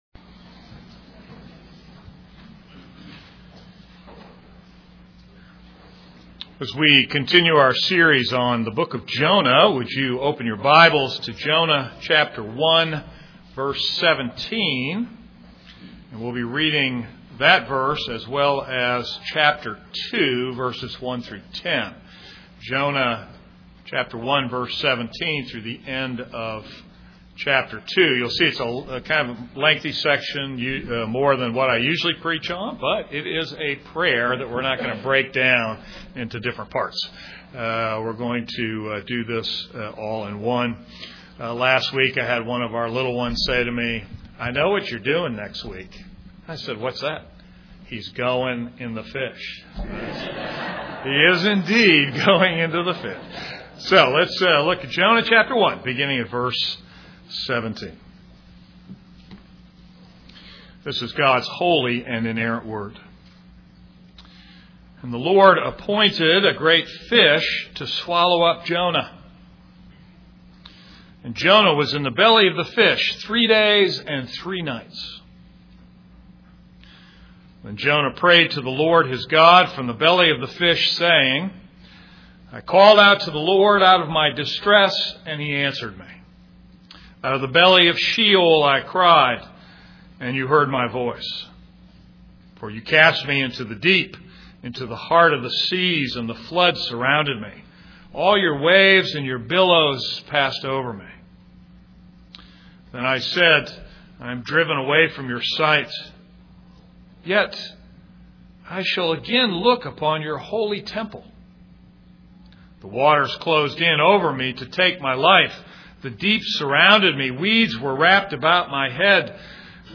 This is a sermon on Jonah 1:17-2:10.